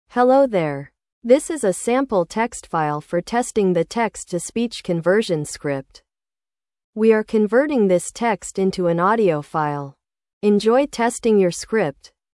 sample_speech_voice.wav